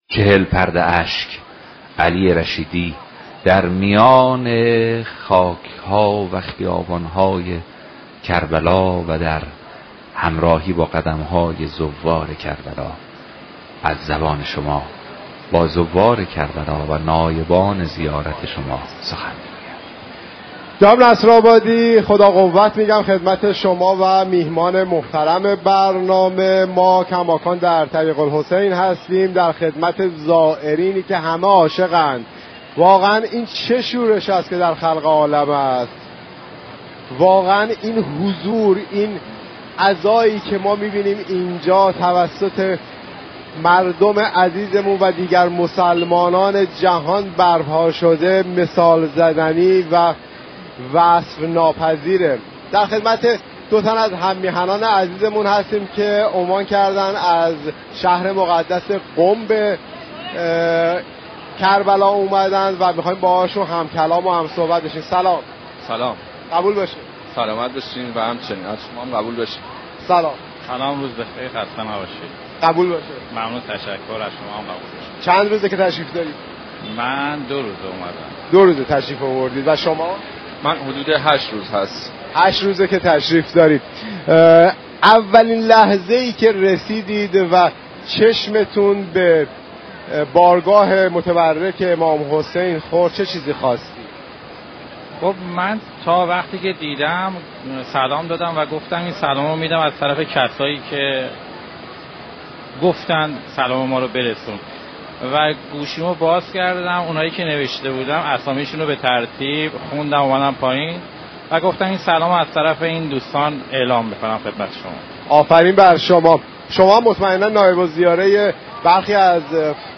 رادیو ایران از خاك‌كربلا با شما سخن می‌گوید
یكی از زائران می گوید به محض رسیدن به كربلا و حضور در حرم امام‌حسین (ع) به نیابت دوستان به حضرت ابراز ارادت كردم. برنامه چهل‌پرده‌اشك روز شنبه 3 شهریور از رادیو ایران پخش شد.